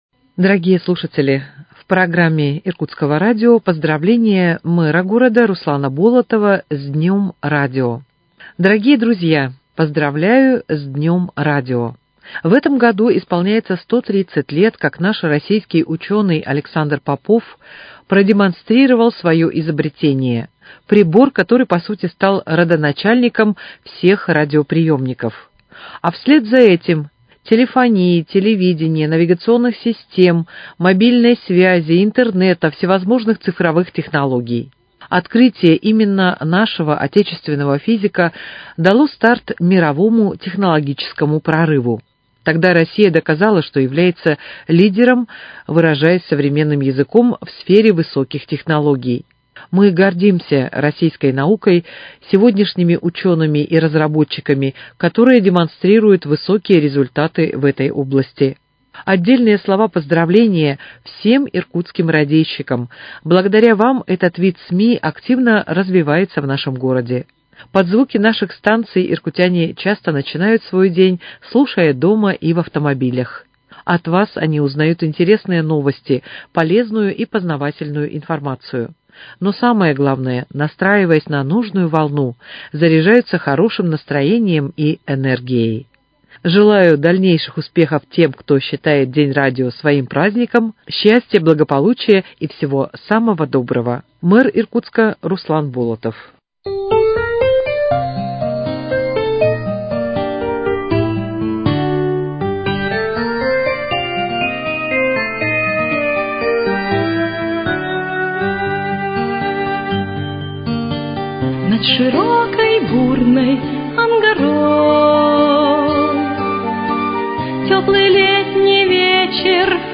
Поздравление мэра г.Иркутска Руслана Болотова с Днем радио